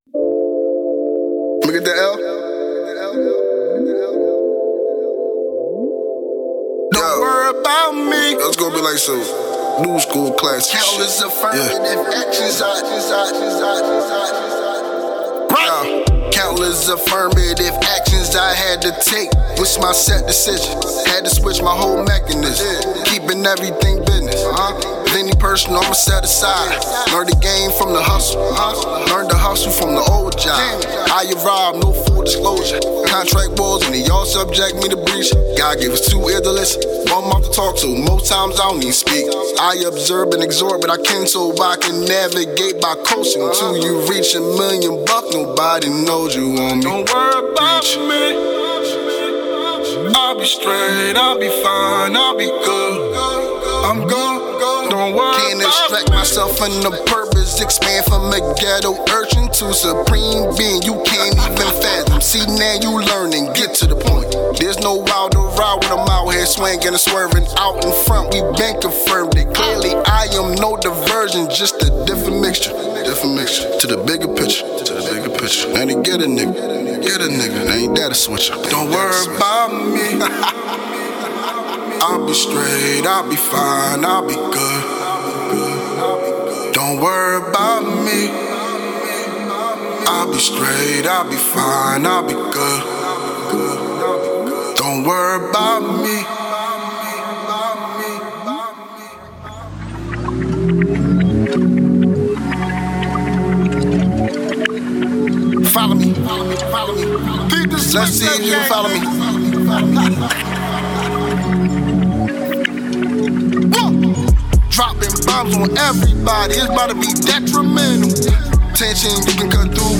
3 in 1 medley